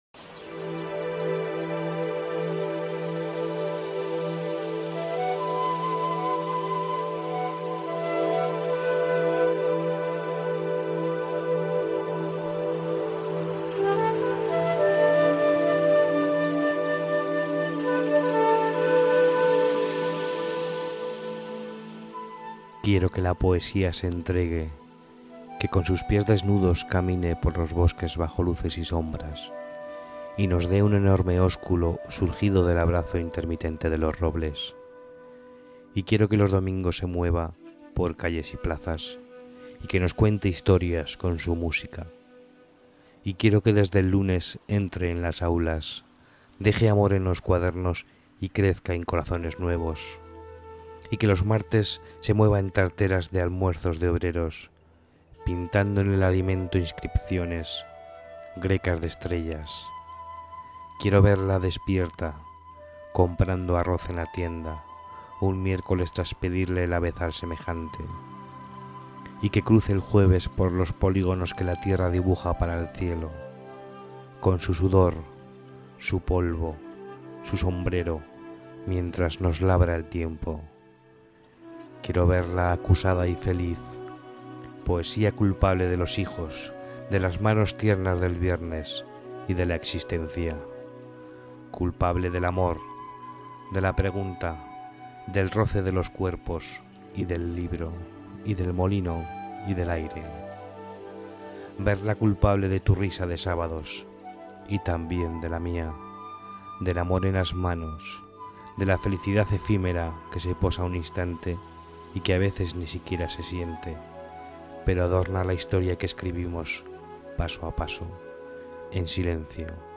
Inicio Multimedia Audiopoemas Quiero.